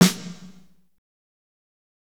Index of /90_sSampleCDs/Northstar - Drumscapes Roland/DRM_AC Lite Jazz/SNR_A_C Snares x